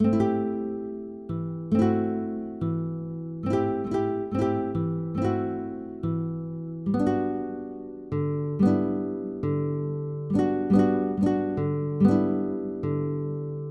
标签： 70 bpm Hip Hop Loops Guitar Acoustic Loops 2.31 MB wav Key : Unknown
声道立体声